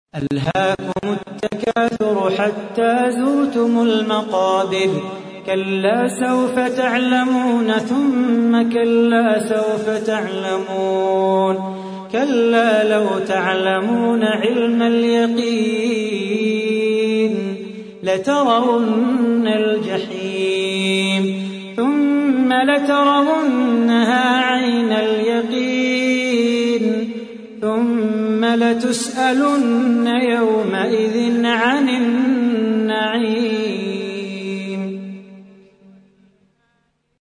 تحميل : 102. سورة التكاثر / القارئ صلاح بو خاطر / القرآن الكريم / موقع يا حسين